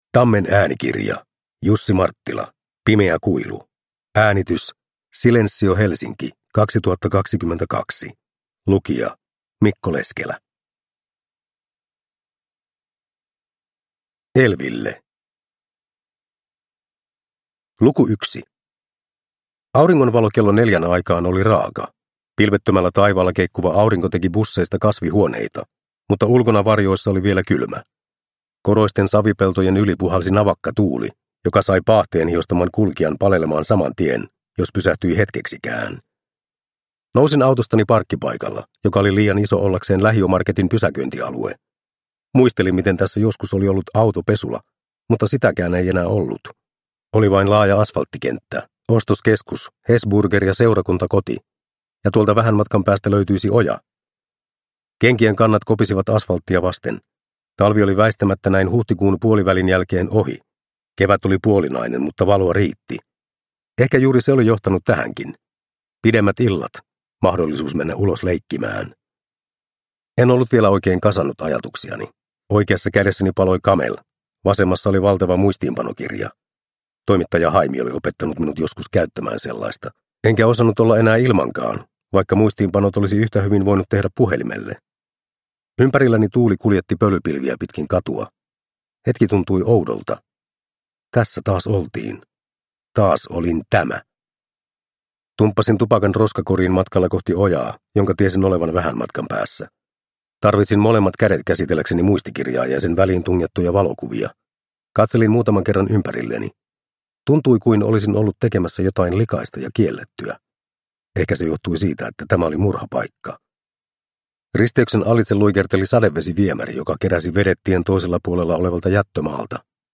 Pimeä kuilu – Ljudbok – Laddas ner